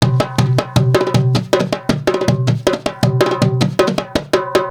PERC 03.AI.wav